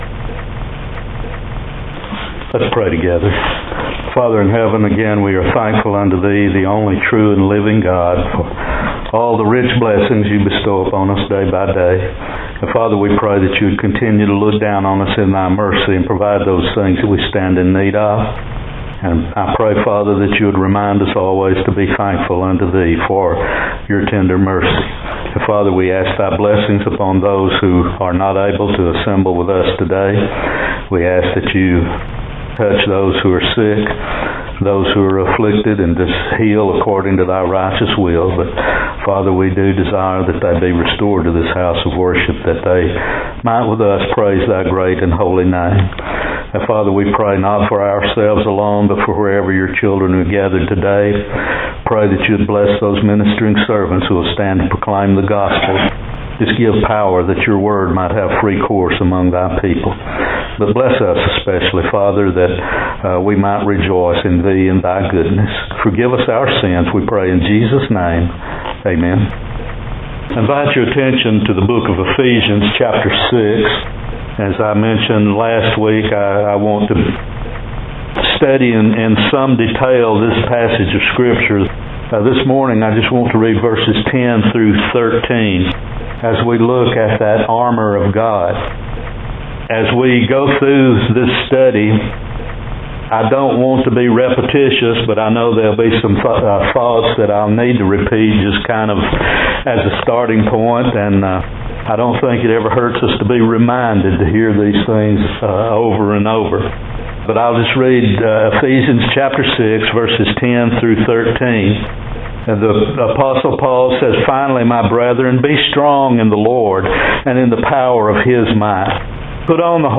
Ephesians 6:10-13, Be Strong In God’s Might Apr 6 In: Sermon by Speaker